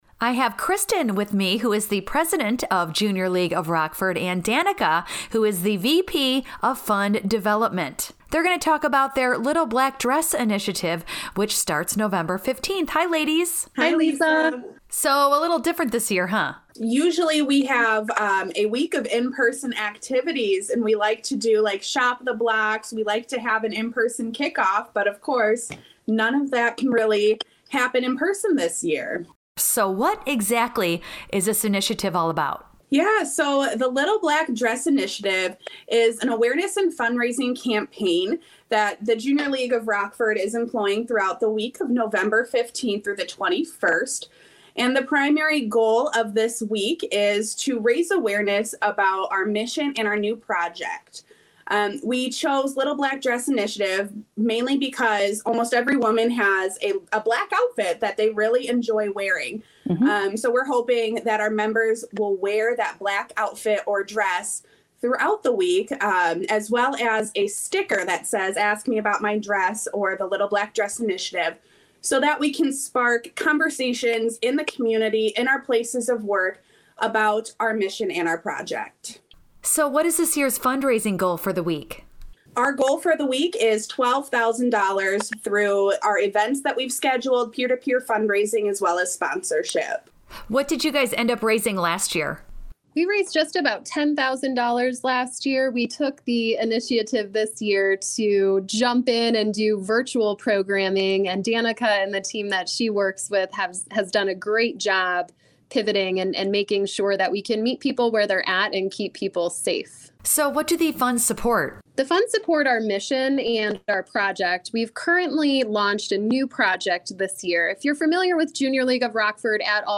Jr-League-morning-interview.mp3